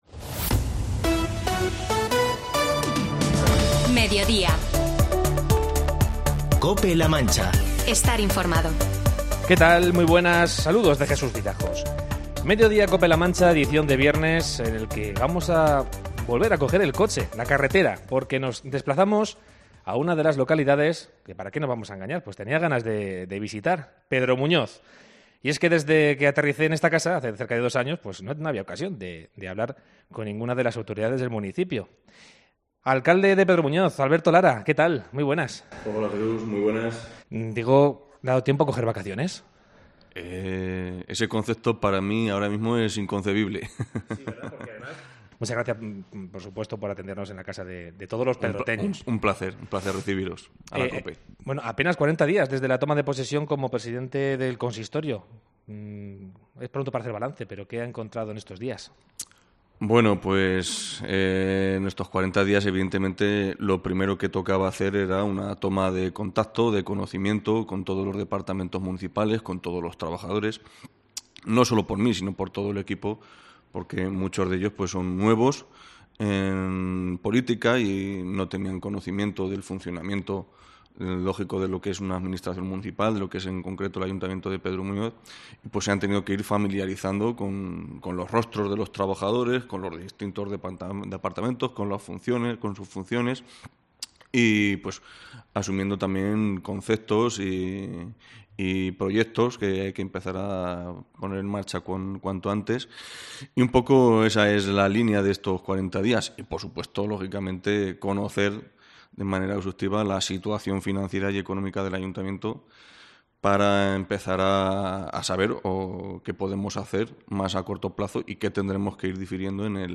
Hoy, en Mediodía Cope La Mancha, hemos viajado a Pedro Muñoz para entrevistar a su alcalde, Alberto Lara, y la concejal de festejos, Alicia Alberca.
Feria y Fiestas en honor a Nuestra Señora de los Ángeles, del 1 al 7 en Pedro Muñoz. Escucha la entrevista completa.